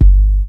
• Classic Hip-Hop Steel Kick Drum G Key 320.wav
Royality free steel kick drum sound tuned to the G note. Loudest frequency: 59Hz
classic-hip-hop-steel-kick-drum-g-key-320-6UZ.wav